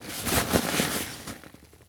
foley_sports_bag_movements_10.wav